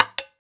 Perc Koopa 1.wav